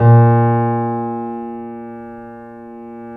Index of /90_sSampleCDs/Roland - Rhythm Section/KEY_YC7 Piano pp/KEY_pp YC7 Mono